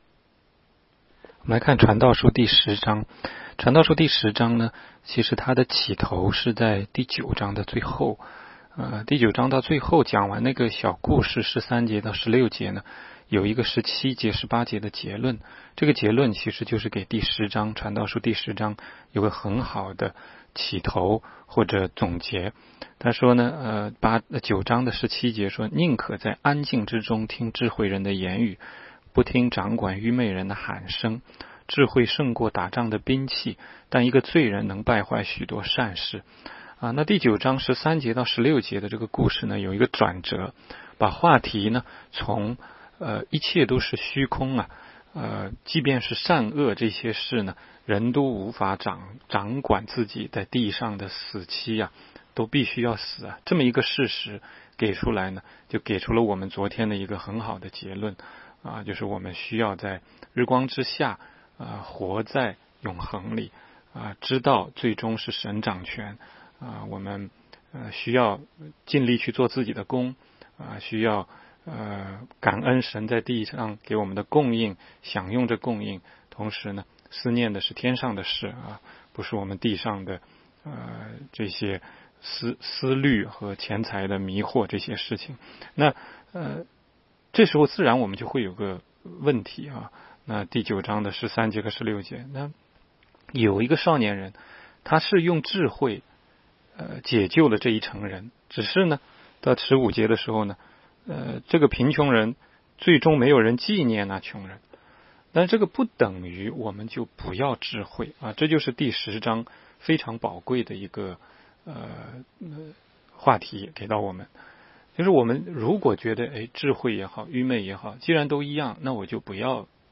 16街讲道录音 - 每日读经 -《 传道书》10章